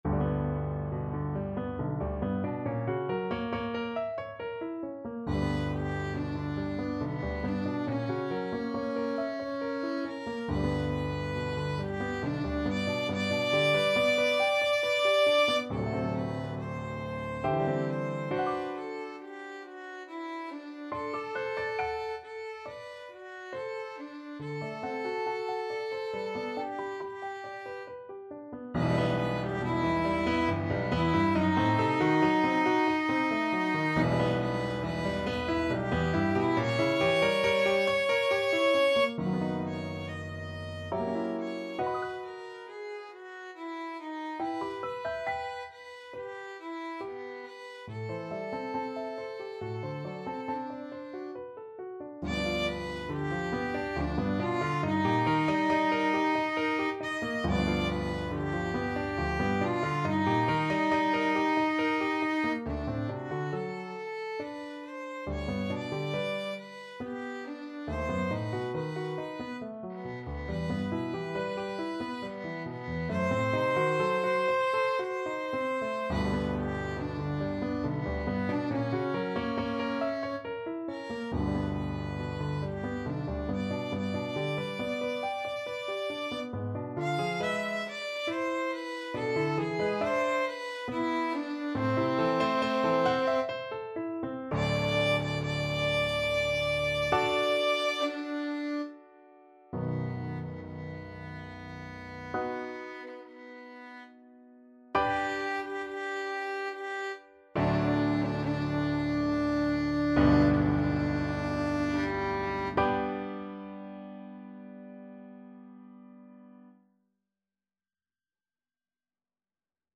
Violin
G major (Sounding Pitch) (View more G major Music for Violin )
3/4 (View more 3/4 Music)
~ = 69 Large, soutenu
Classical (View more Classical Violin Music)